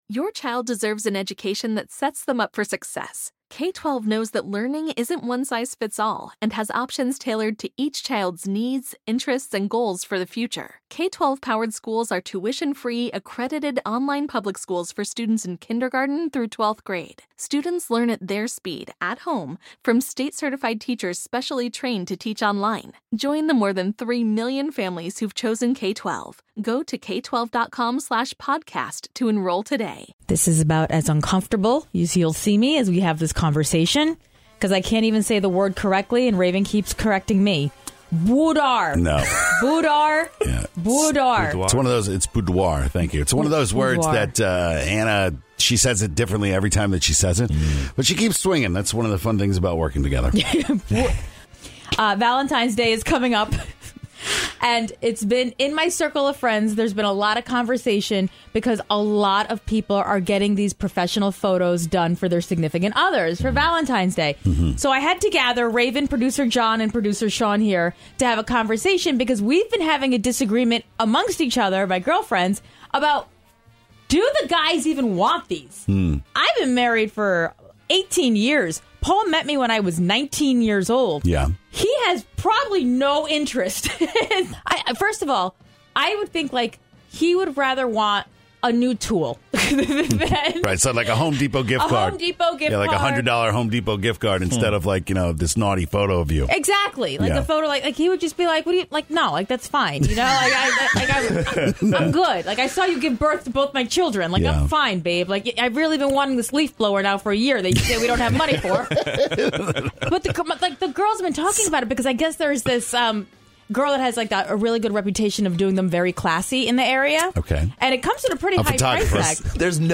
Five calls say it all, what do you think?